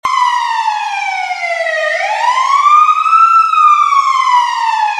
fire_engine.wav